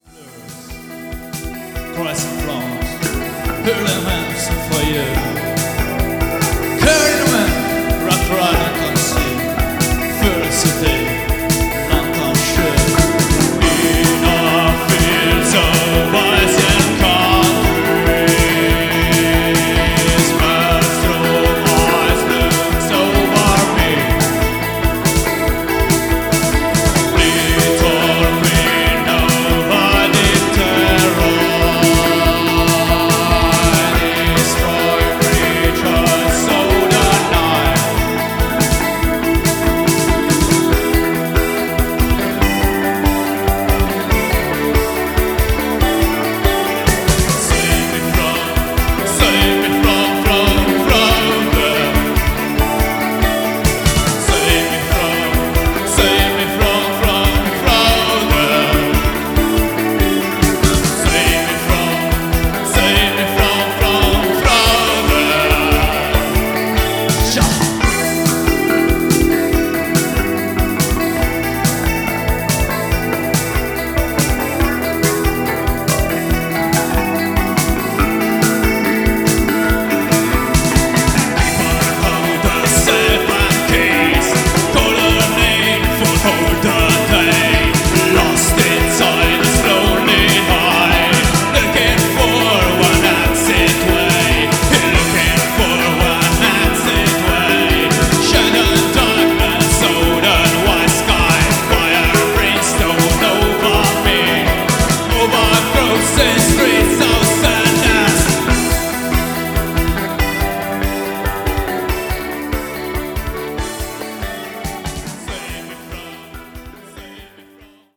Formed in ’92 with voice, distorted bass and drums.